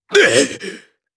Chase-Vox_Damage_jp_01.wav